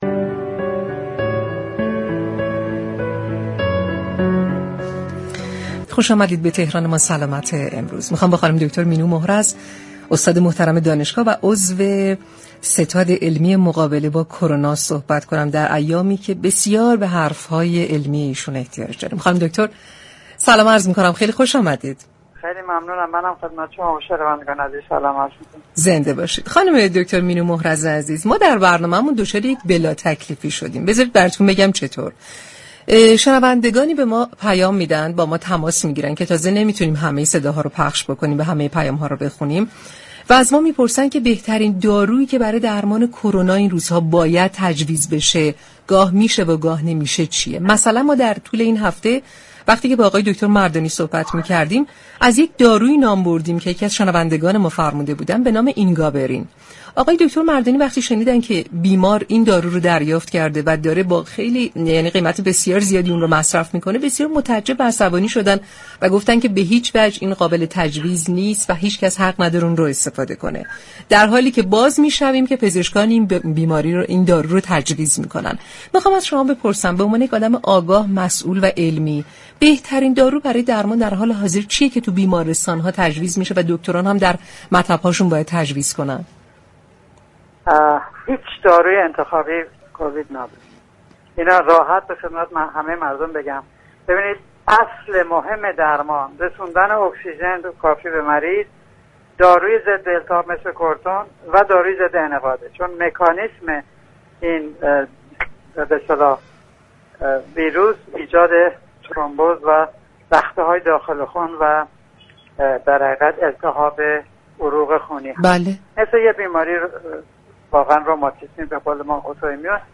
در گفتگو با برنامه تهران ما سلامت